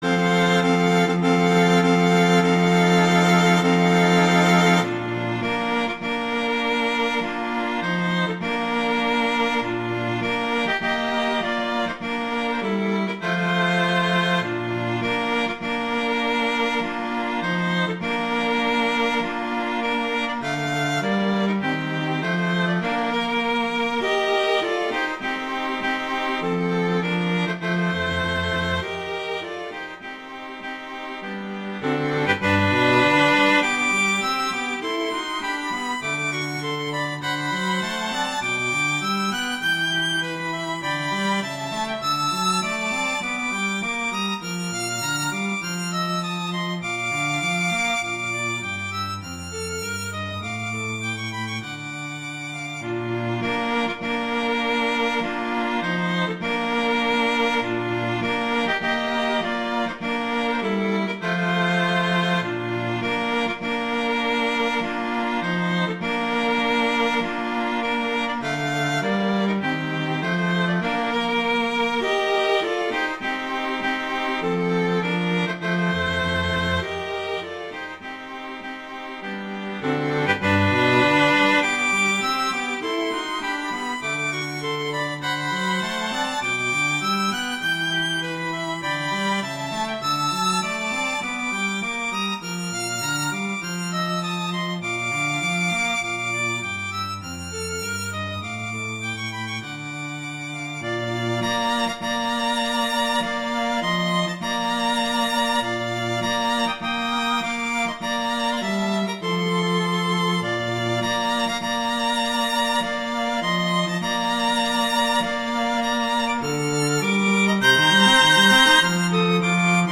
Instrumentation: two violins & cello
arrangements for two violins and cello
wedding, traditional, classical, festival, love, french